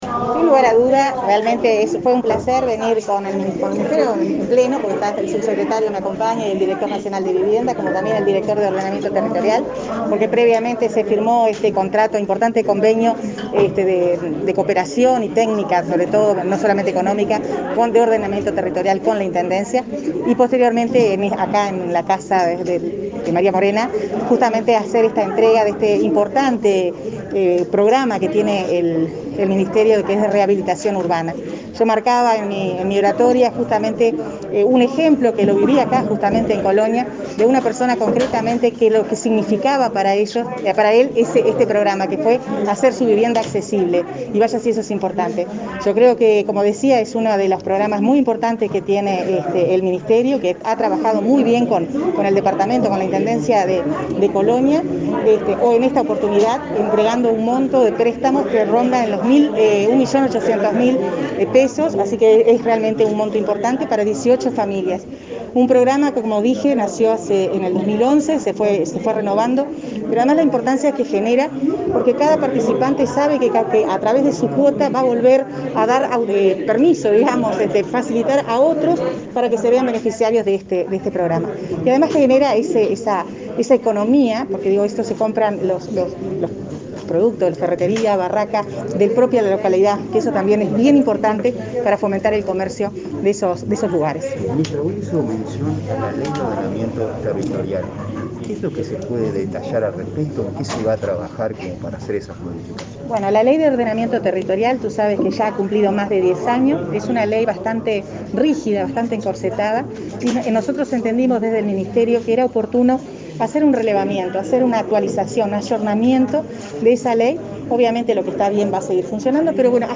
Declaraciones a la prensa de la ministra de Vivienda y Ordenamiento Territorial, Irene Moreira, en Colonia
Autoridades del Ministerio de Vivienda y Ordenamiento Territorial y de la Intendencia de Colonia firmaron un convenio referido al territorio departamental y la información concerniente a esa área. La secretaría de Estado entregó préstamos para mejoras de vivienda en ese departamento, este 8 de setiembre. Tras los actos, la ministra Moreira brindó declaraciones a medios informativos.